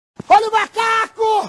Grito olha o macaco do Sérgio Malandro. Essa é a versão curta.